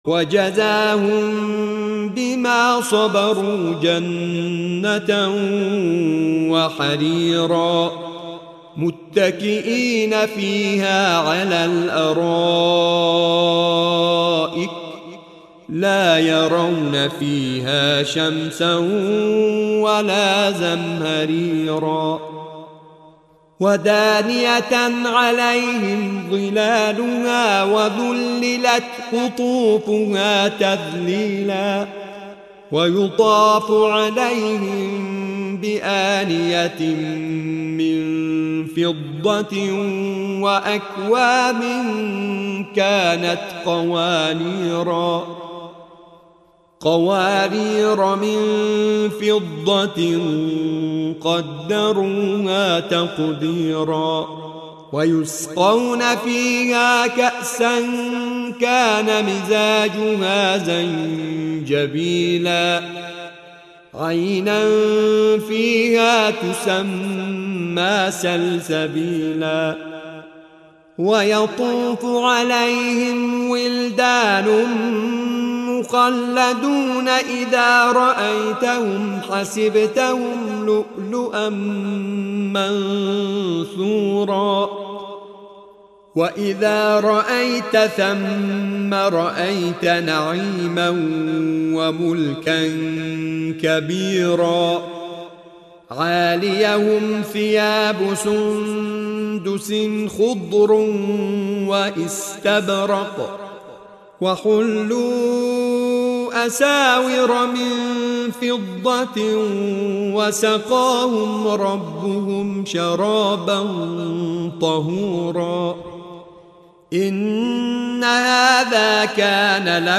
سوره مبارکه انسان آیه 22 تا 12/ نام دستگاه موسیقی: حجاز